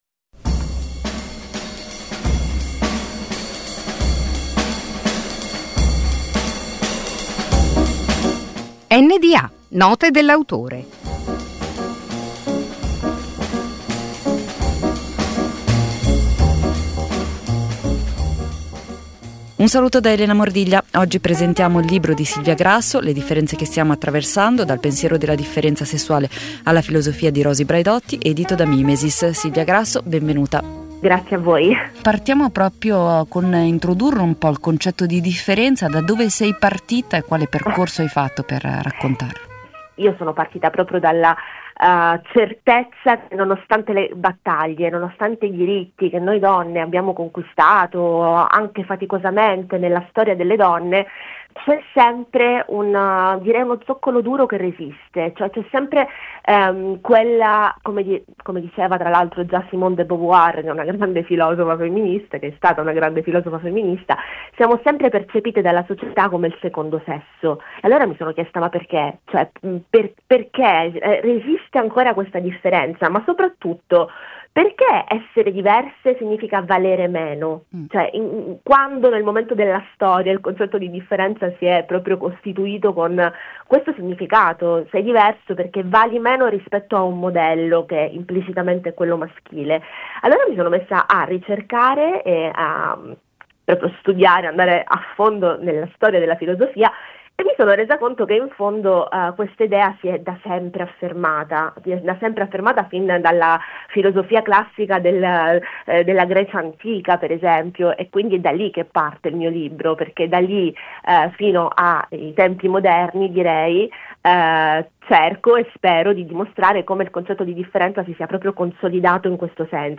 Un appuntamento quasi quotidiano, sintetico e significativo con un autore, al microfono delle voci di Radio Popolare. Note dell’autore è letteratura, saggistica, poesia, drammaturgia e molto altro.